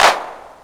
INSTCLAP01-R.wav